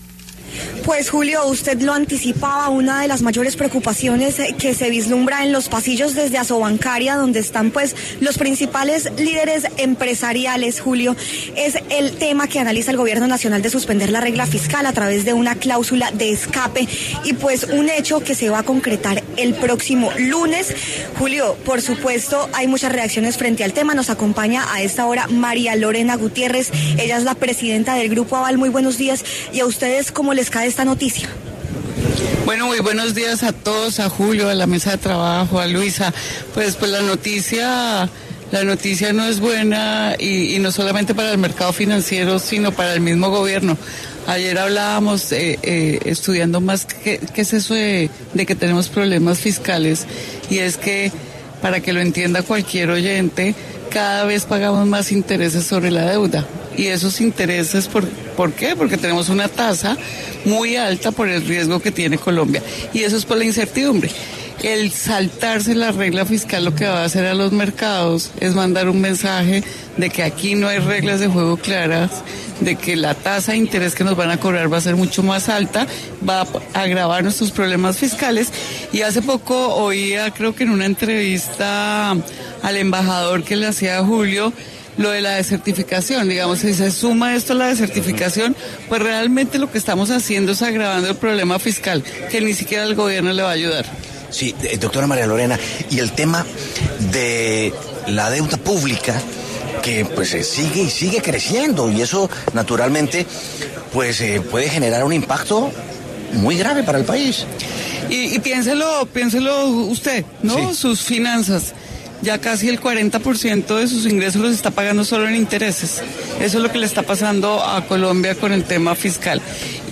María Lorena Gutiérrez, presidenta Grupo Aval, pasó por los micrófonos de La W, sobre la preocupación tras conocerse que el Gobierno analiza suspender la regla fiscal a través de una cláusula de escape, hecho que se va a concretar el próximo lunes.